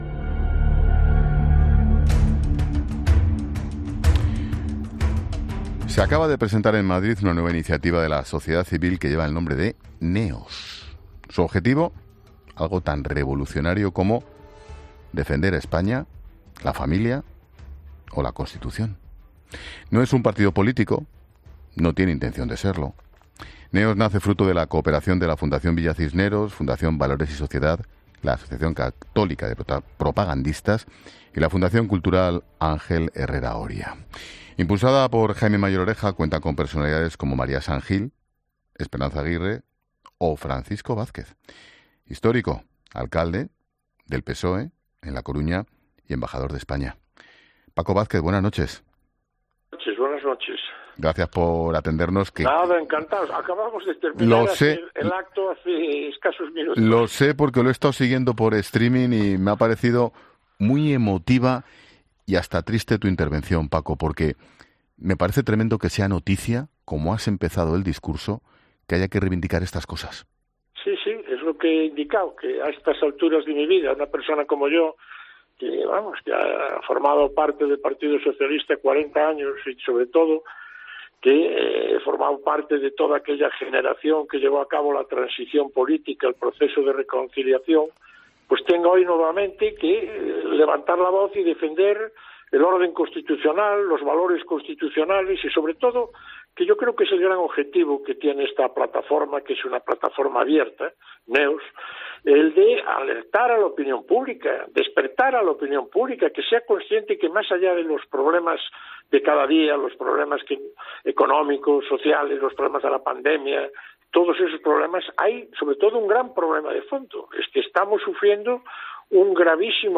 Vázquez ha pasado por los micrófonos de 'La Linterna' y ha reflexionado sobre esa presentación y el motivo por el que nace NEOS.